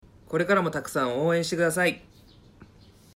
選手ボイス